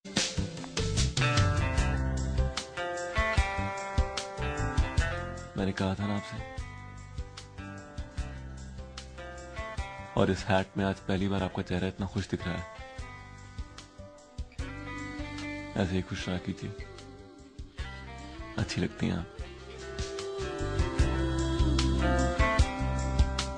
Guitar Ringtones